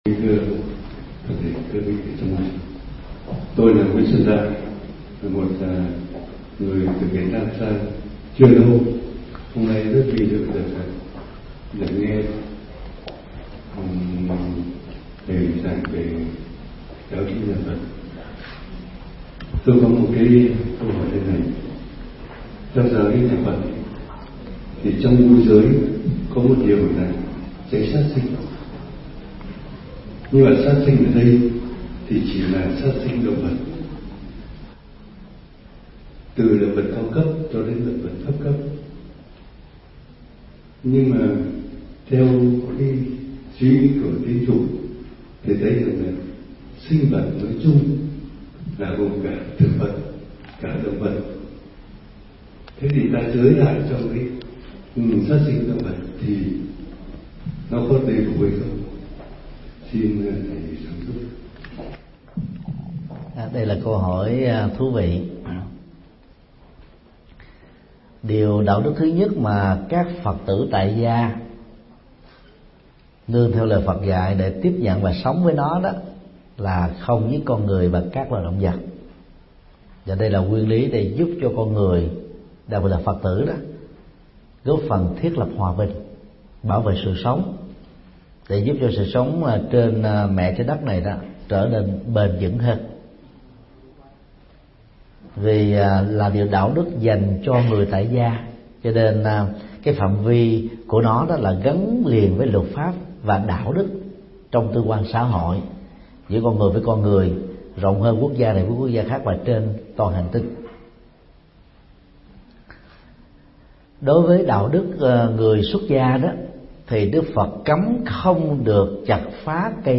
Vấn đáp: Giới sát sinh đối với thực vật